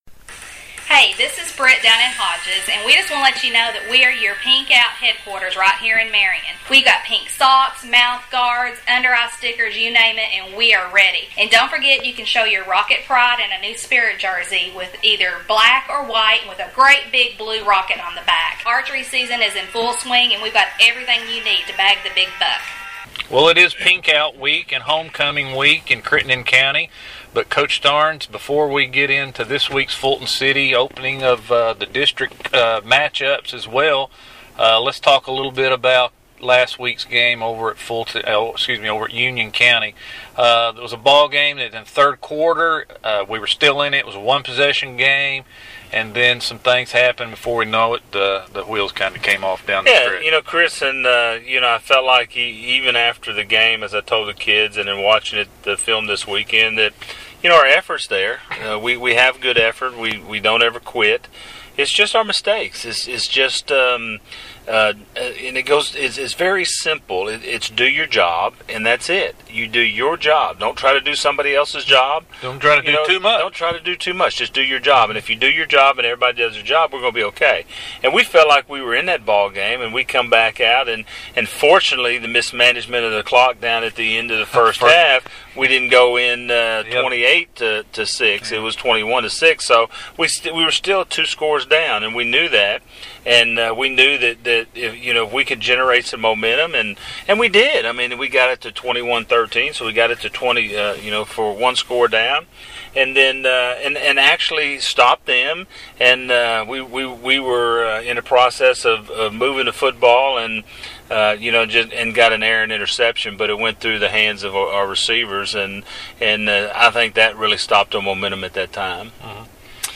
PODCAST: Union County Post-Game Interview